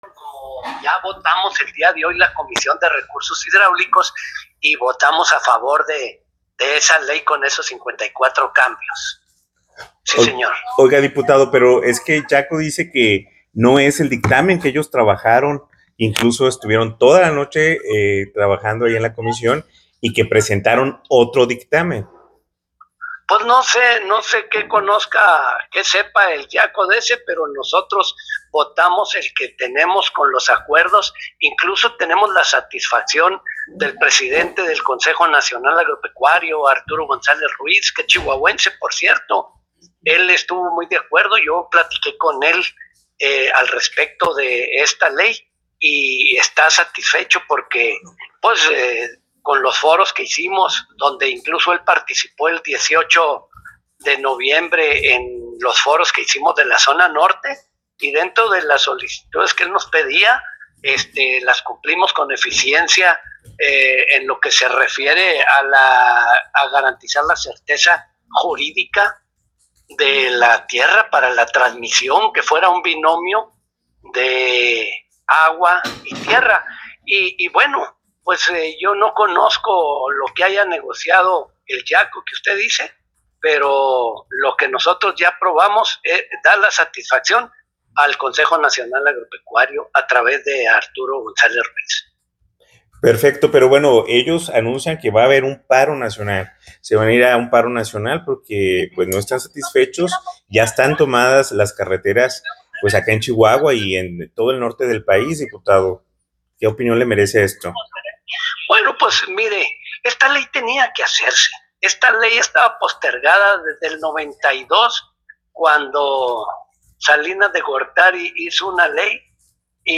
En una llamada telefónica, el diputado federal por el Partido del Trabajo (PT), Roberto Corral Ordoñez, reconoció que desconoce los acuerdos específicos alcanzados con productores y campesinos de Chihuahua durante los trabajos de la Comisión de Recursos Hídricos, pero confirmó que votó a favor del dictamen final que contiene “54 cambios” y que, de cualquier forma, apoyará la nueva Ley General de Aguas en el pleno.